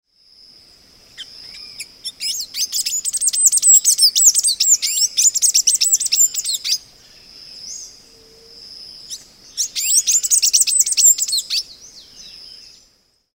Thraupis palmarum
Aprecie o canto do
Sanhaço-do-coqueiro